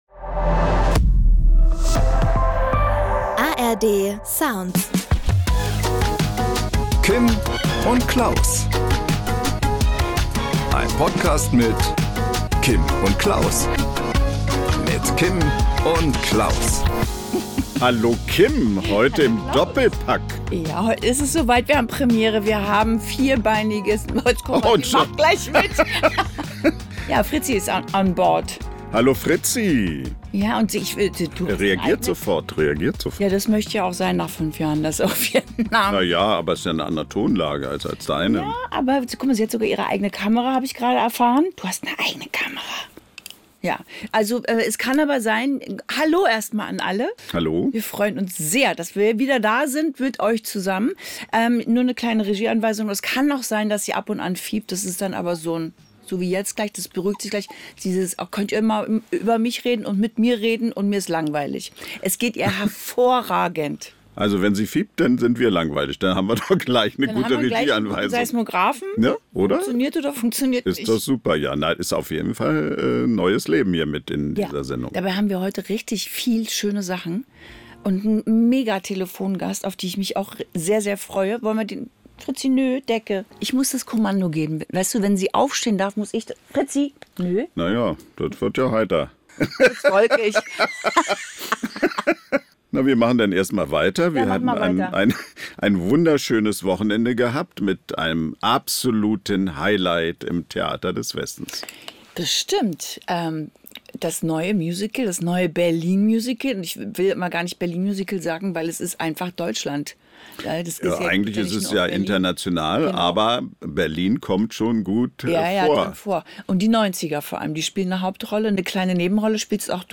Am Promitelefon sorgt Schlagerstar Beatrice Egli für gute Laune und verrät ihr Geheimrezept für grenzenlosen Optimismus.